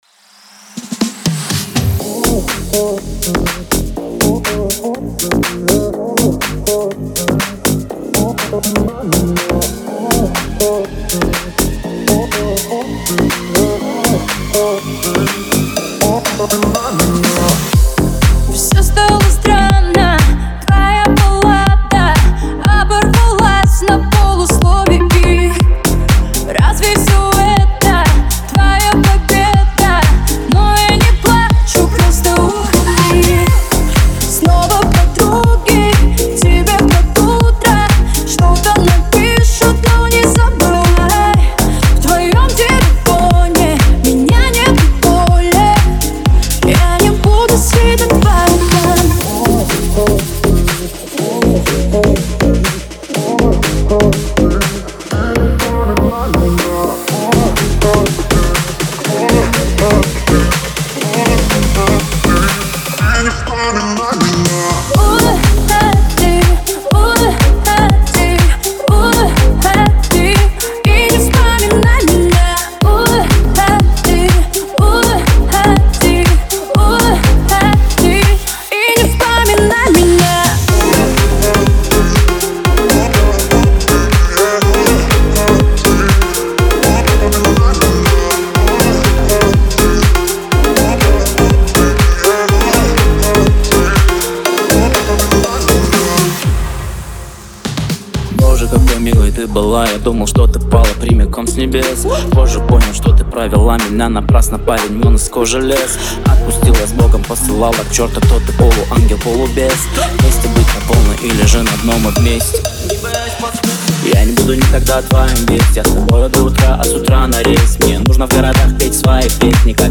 это динамичная композиция в жанре хип-хоп с элементами R&B